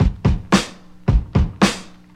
• 110 Bpm Drum Groove C Key.wav
Free drum beat - kick tuned to the C note. Loudest frequency: 933Hz
110-bpm-drum-groove-c-key-lQr.wav